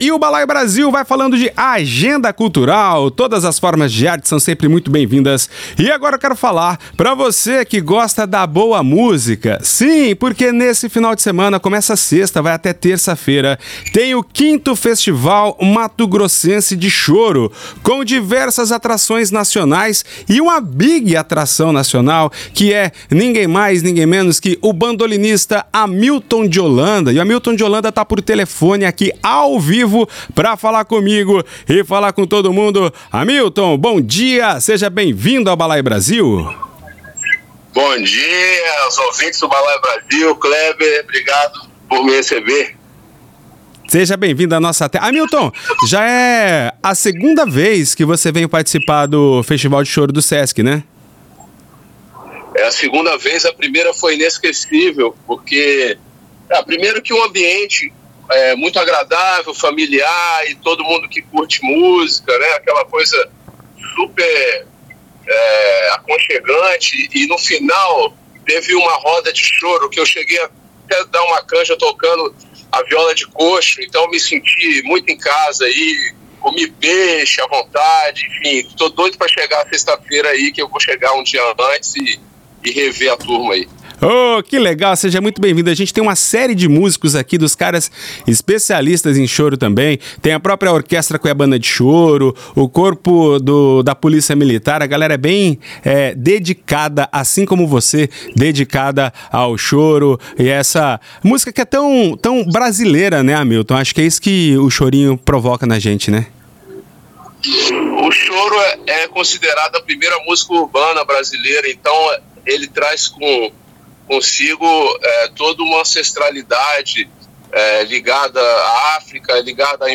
No programa Balaio Brasil, o bandolinista Hamilton de Holanda convida para o 5° Festival de Choro de MT.